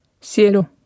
Speech synthesis Martha to computer or mobile phone
Speech Synthesis Martha